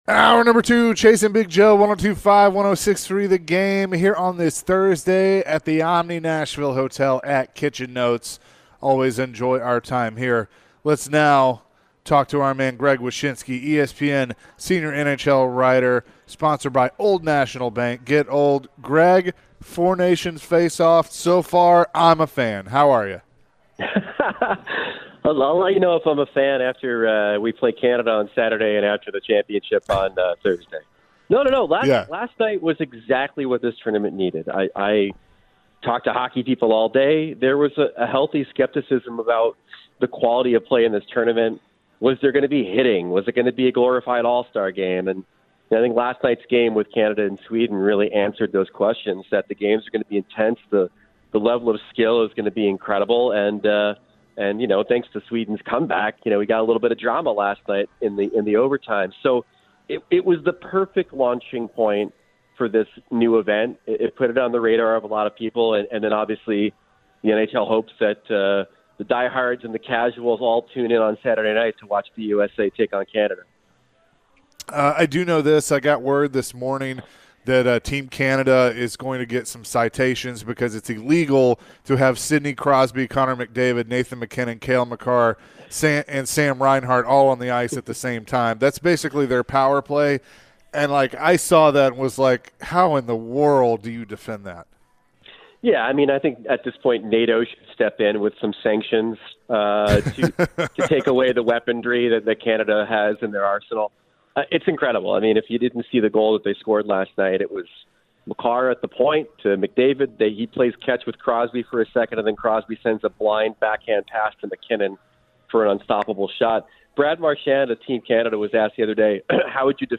The guys caught up with ESPN NHL Senior Writer Greg Wyshynski. Greg mentioned the 4 Nations tournament. Could this tournament benefit Juuse Saros after his lackluster NHL performance this season?